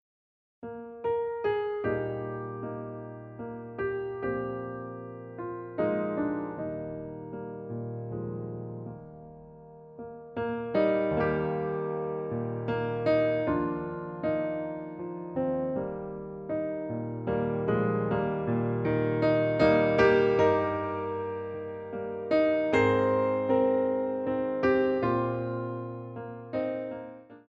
PORT DE BRAS